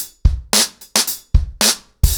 BlackMail-110BPM.1.wav